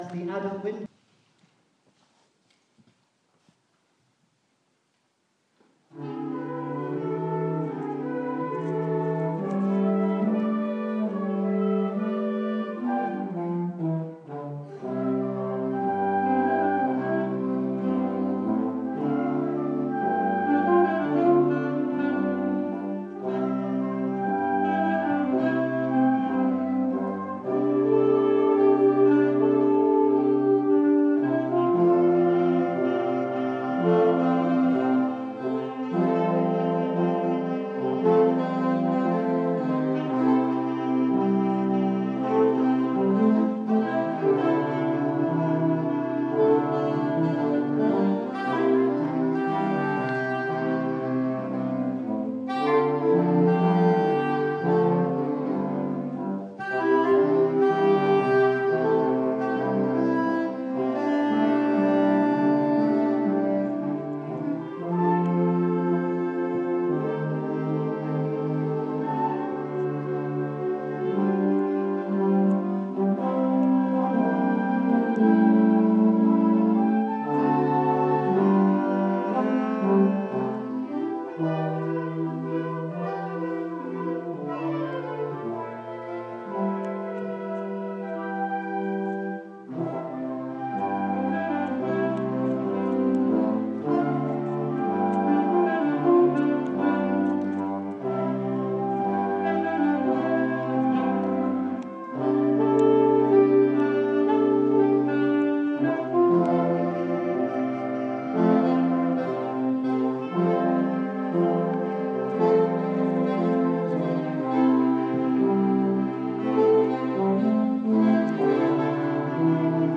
Adult Wind Band